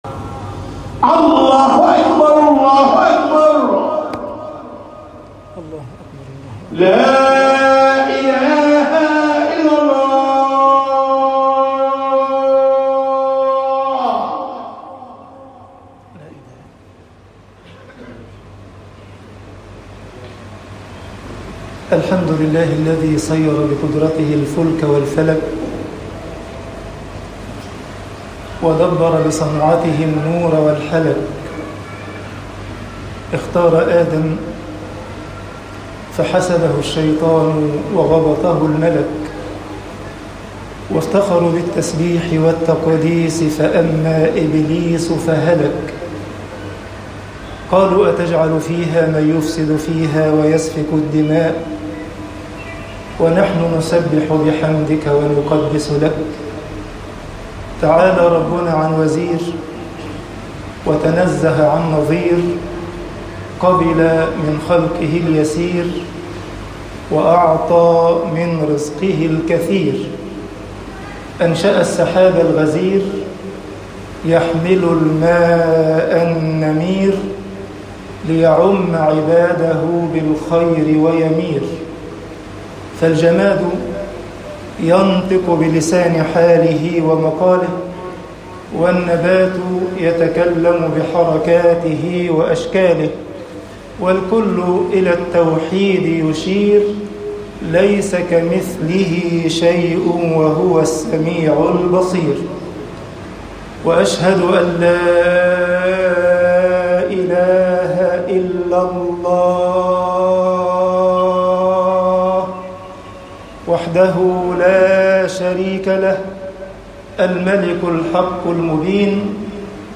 خطب الجمعة - مصر ليسَ مِنَّا طباعة البريد الإلكتروني التفاصيل كتب بواسطة